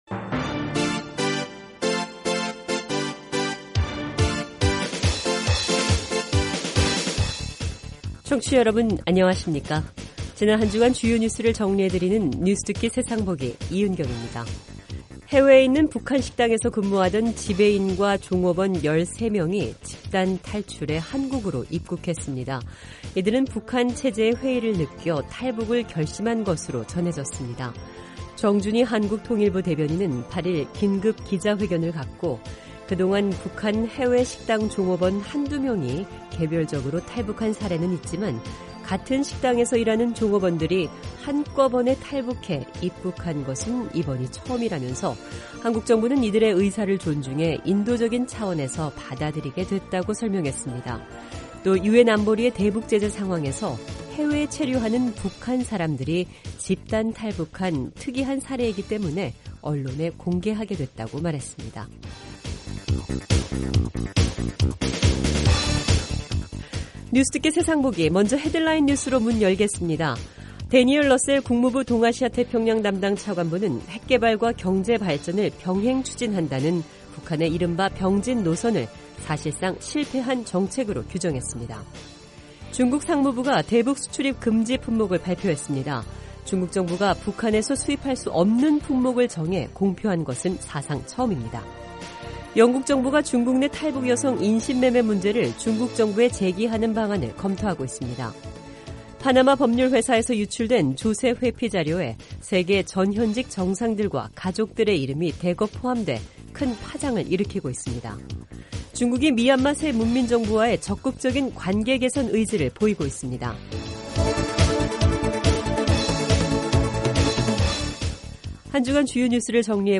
지난 한주간 주요 뉴스를 정리해 드리는 뉴스듣기 세상보기 입니다. 중국 상무부가 대북 수출입 금지 품목을 발표했습니다. 파나마 법률회사에서 유출된 조세회피 자료에 세계 전 현직 정상들과 가족들의 이름이 대거 포함돼, 큰 파장을 일으키고 있습니다.